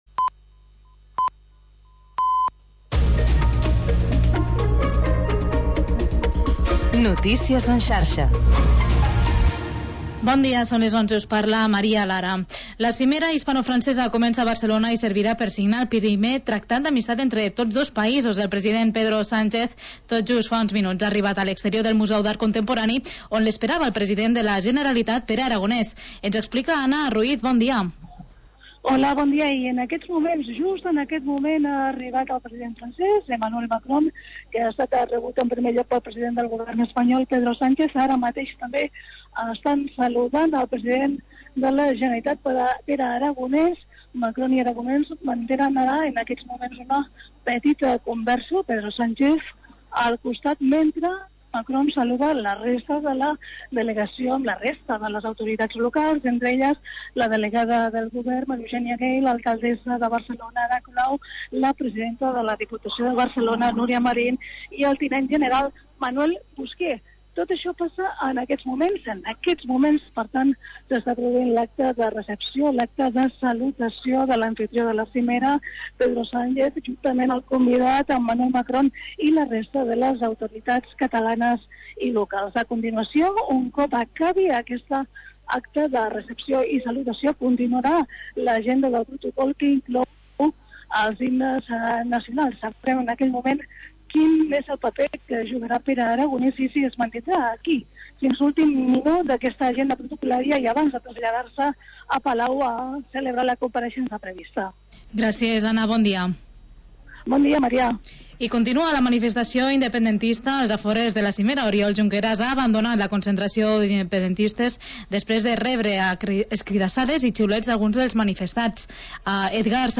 Magazín local d'entreteniment